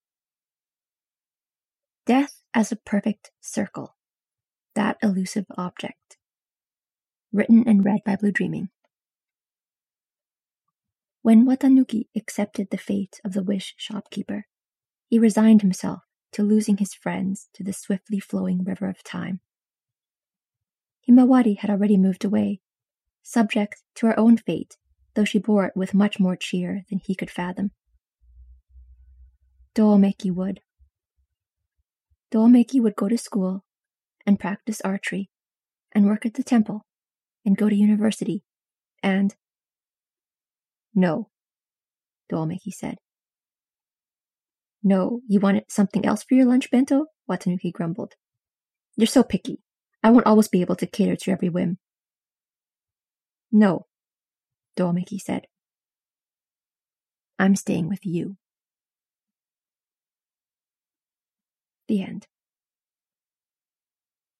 no music: